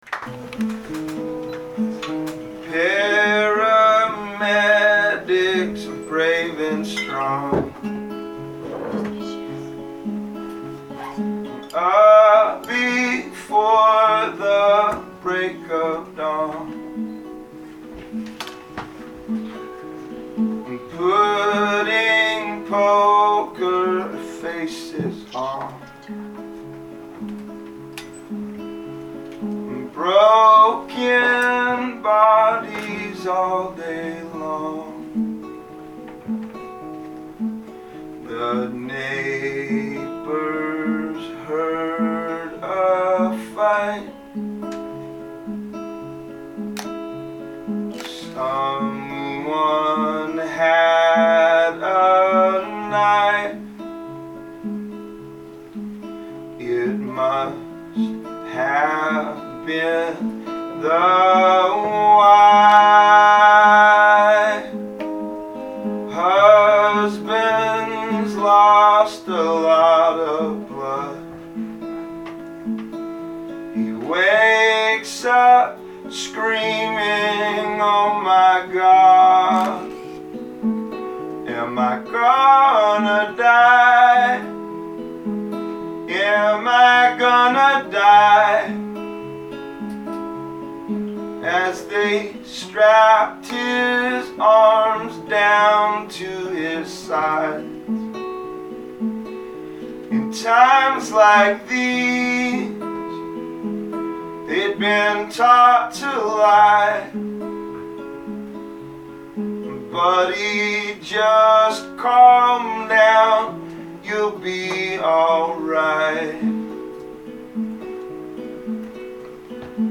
An intimate house performance